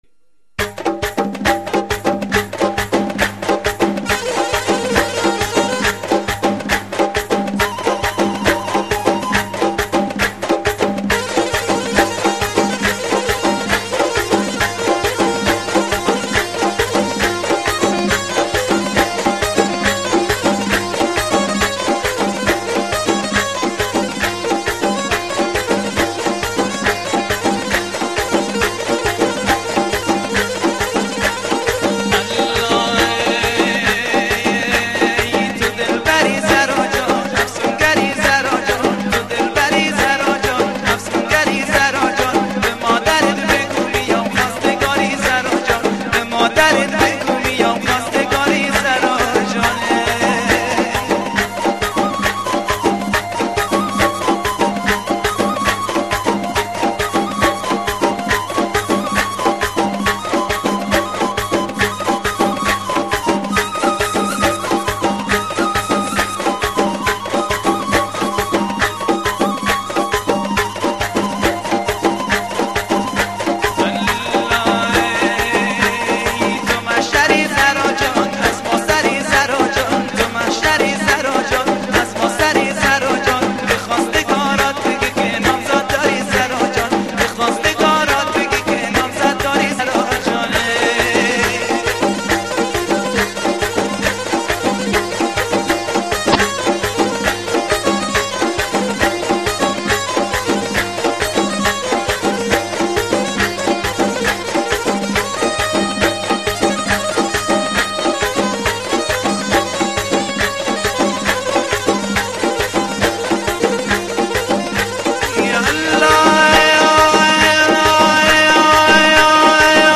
دانلود آهنگ محلی خراسانی